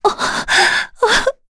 Yuria-Vox_Sad_kr.wav